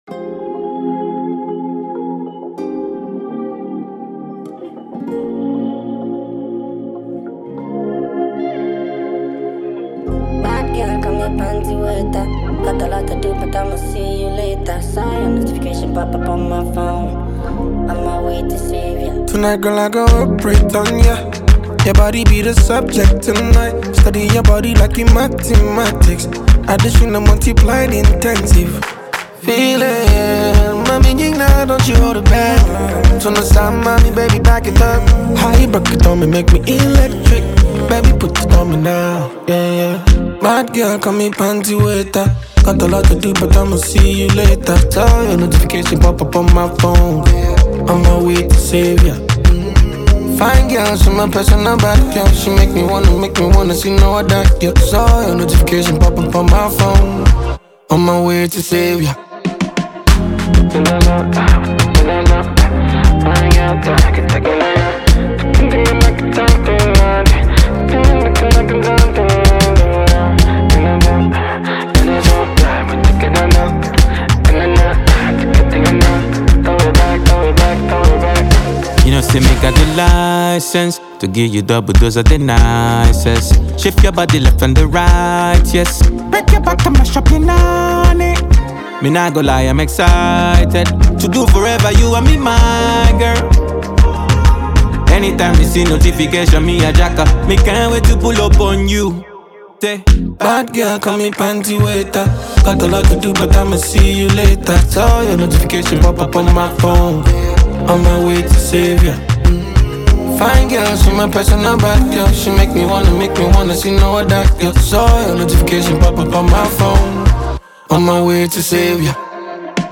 Afropop